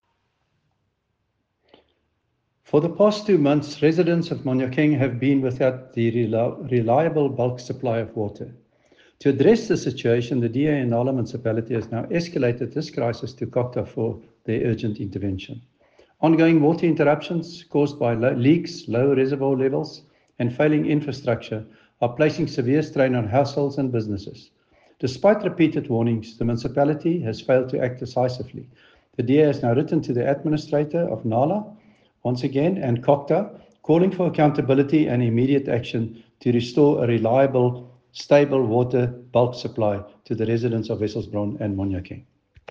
Afrikaans soundbites by Cllr David Ross and